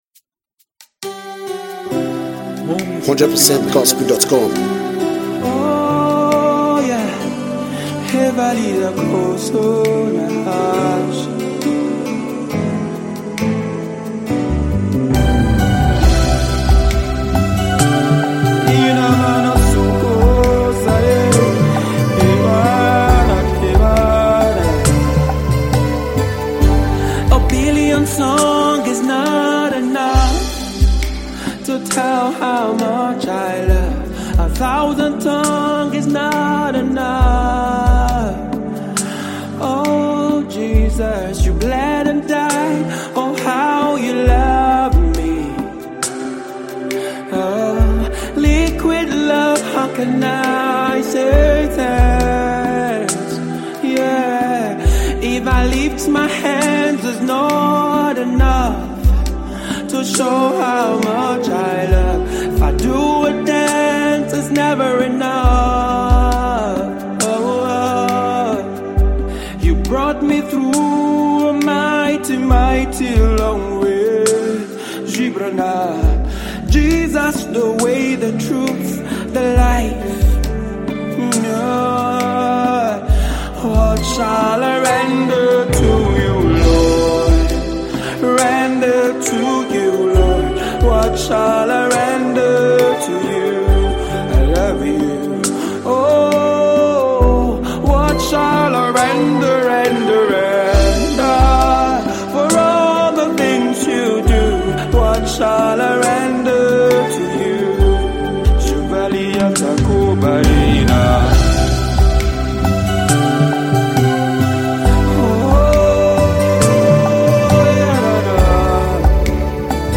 The Nigerian gospel-rational singer and songwriter
worship anthem